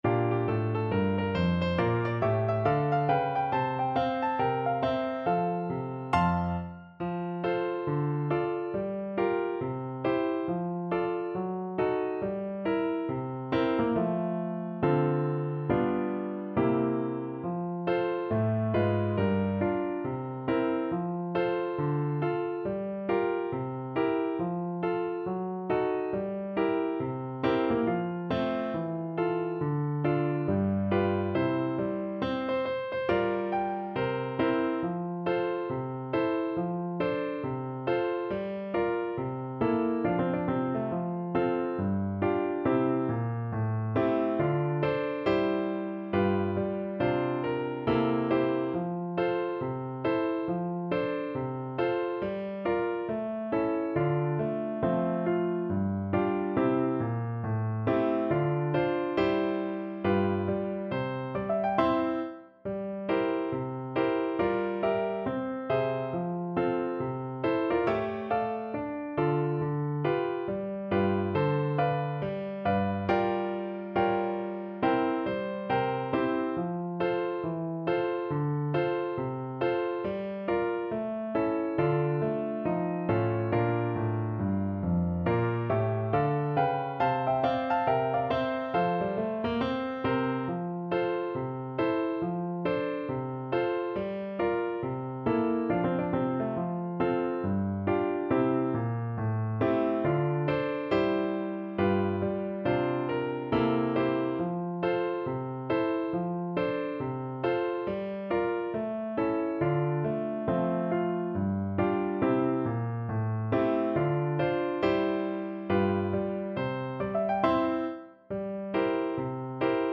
4/4 (View more 4/4 Music)
With a swing =c.69